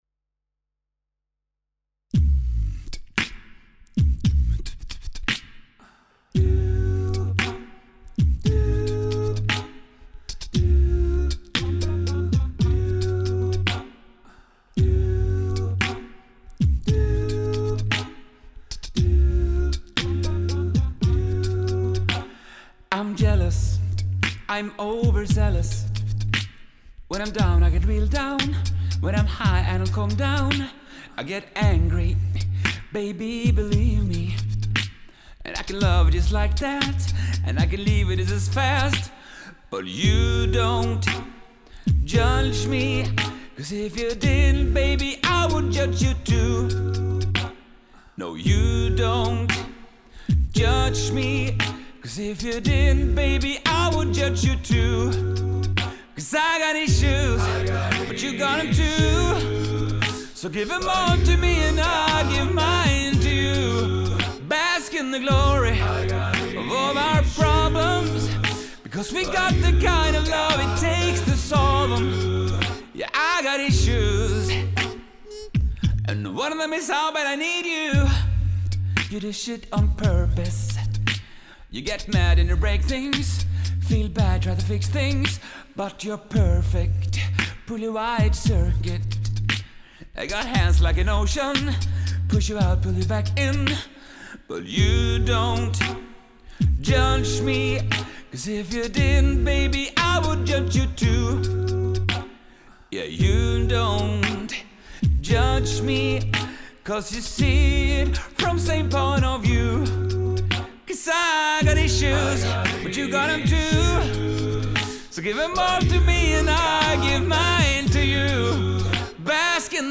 Stemmen hans spenner over et stort register
benyttet mer og mer en såkalt loopstation